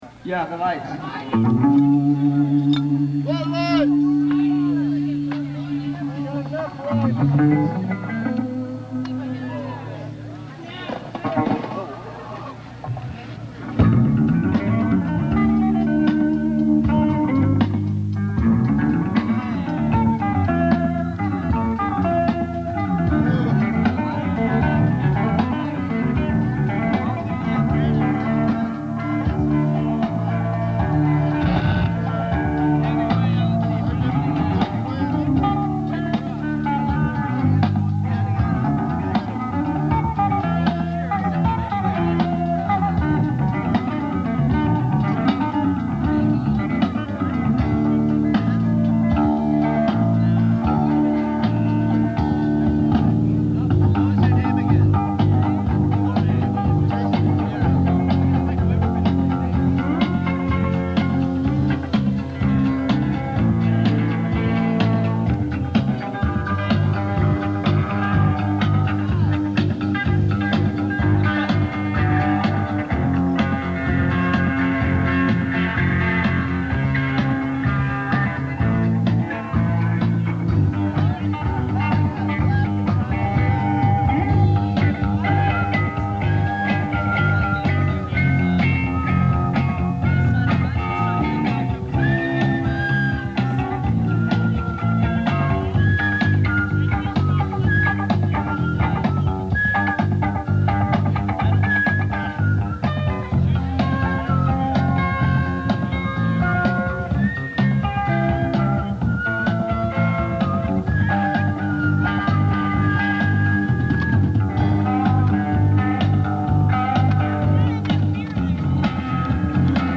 (100 miles east of Victorville, CA)